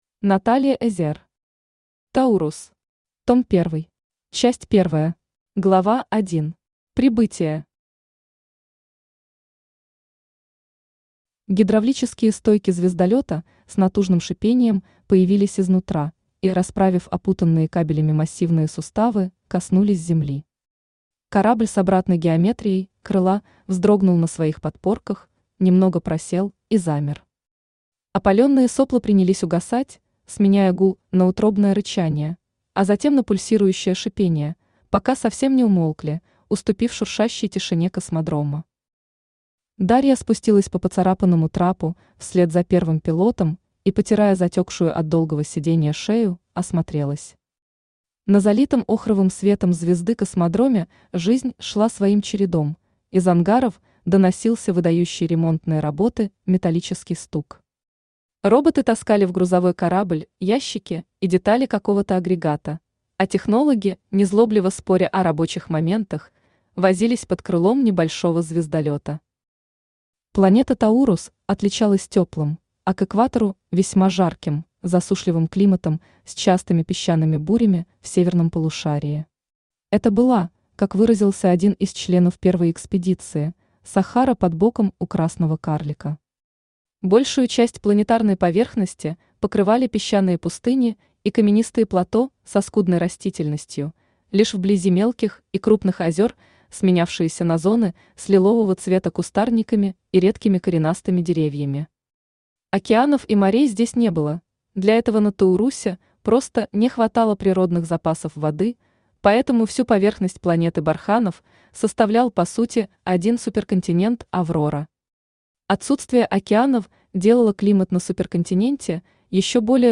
Аудиокнига Таурус. Том первый | Библиотека аудиокниг
Том первый Автор Наталья Эзер Читает аудиокнигу Авточтец ЛитРес.